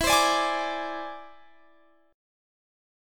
Listen to E+7 strummed